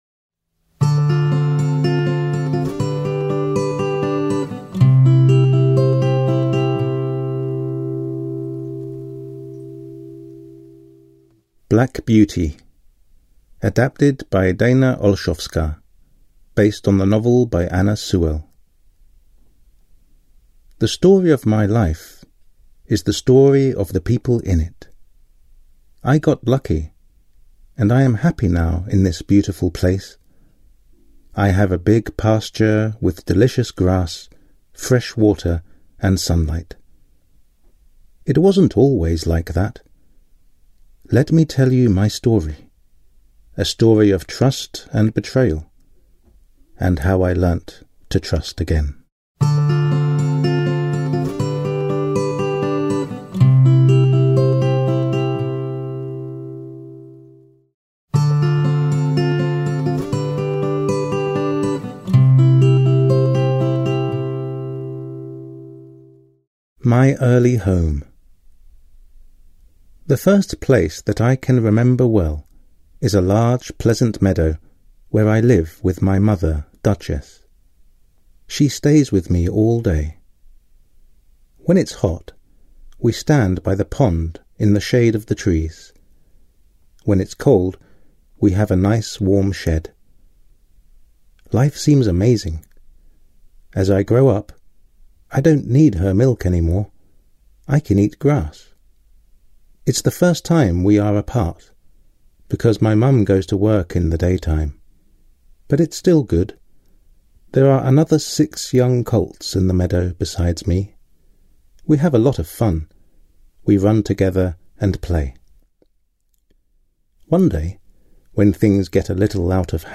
AudioKniha ke stažení, 13 x mp3, délka 57 min., velikost 51,8 MB, anglicky